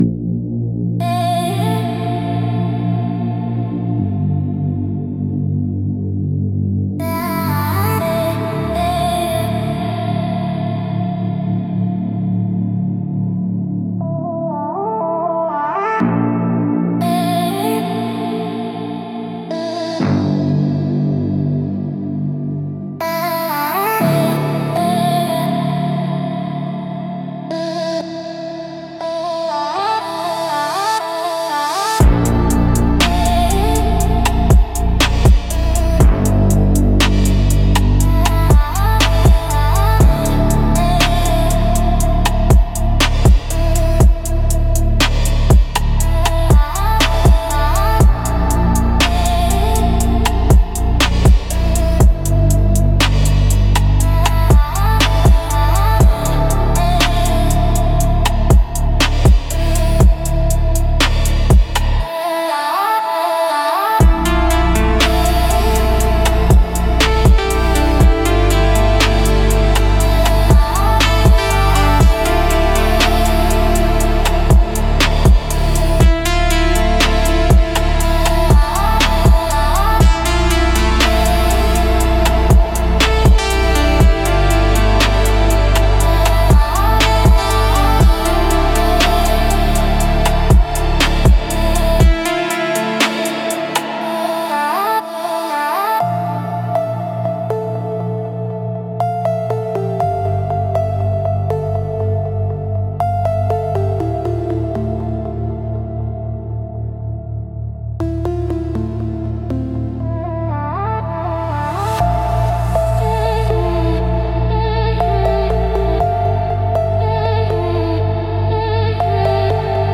Instrumental - Where the Pads Collapse -3.43